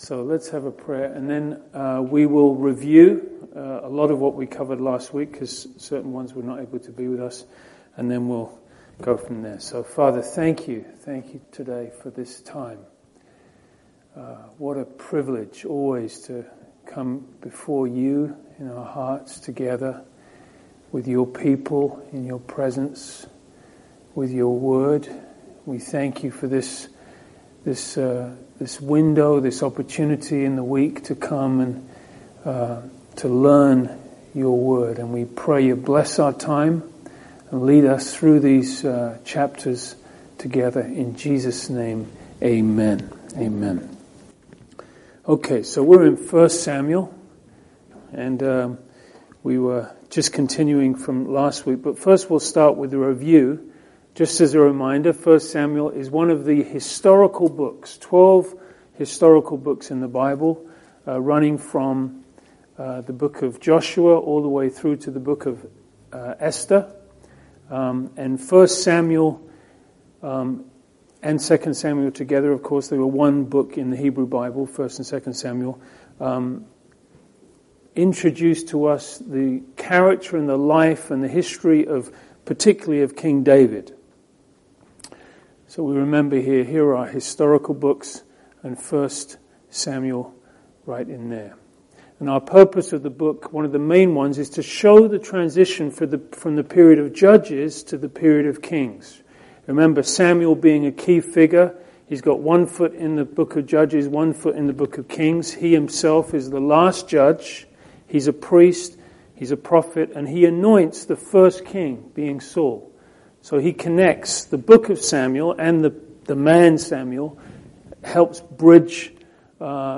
1 Samuel Part 3 (Survey Class)